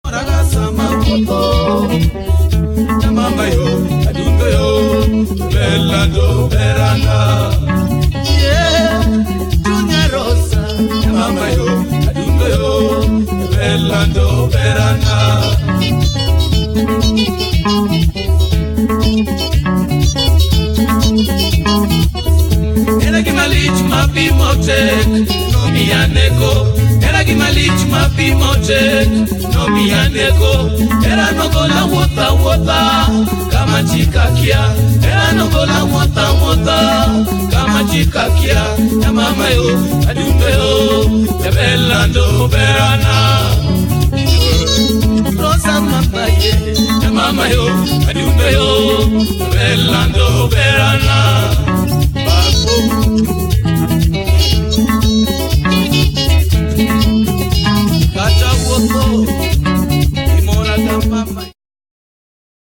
benga music
solid base and sweet guitar work